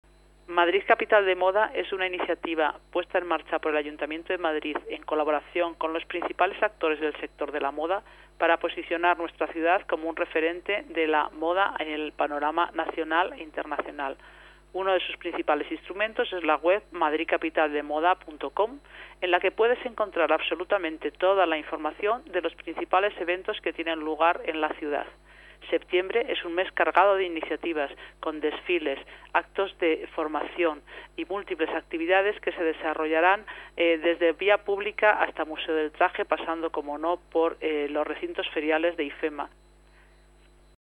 Nueva ventana:Declaraciones de Concha Díaz de Villegas sobre Madrid Capital de Moda